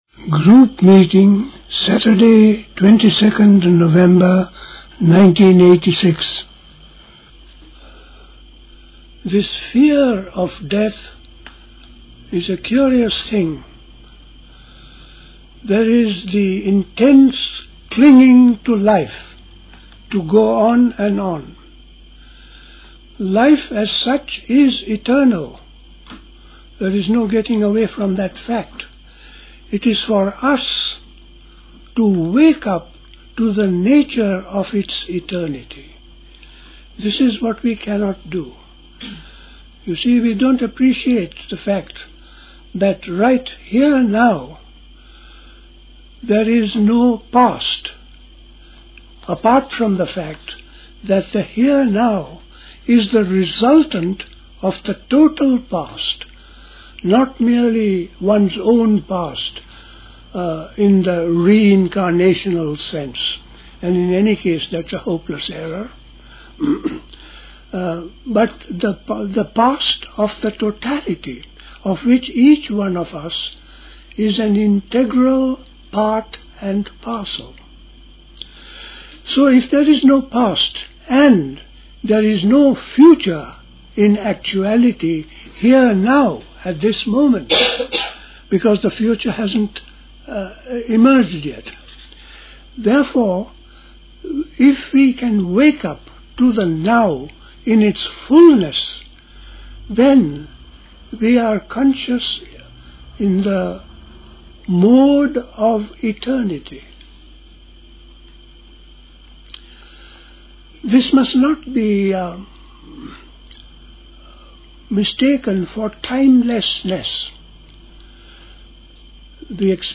A talk
at Dilkusha, Forest Hill, London on 22nd November 1986